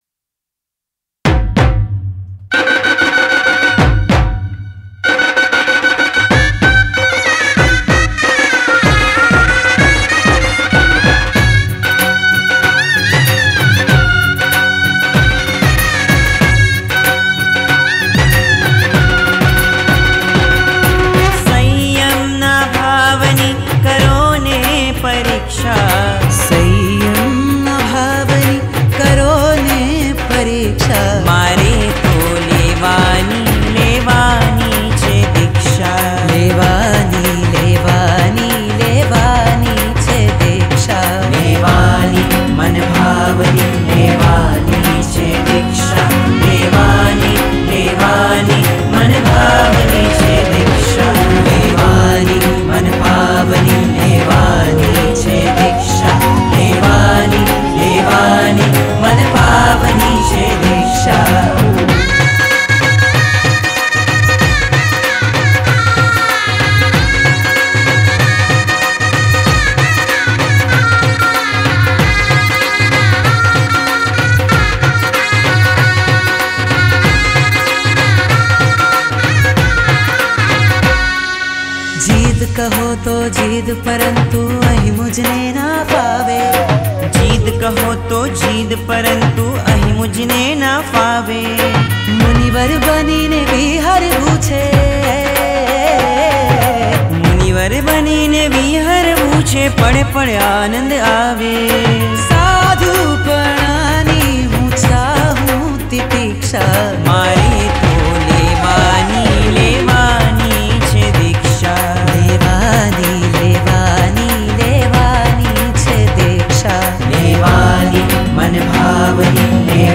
Jain Song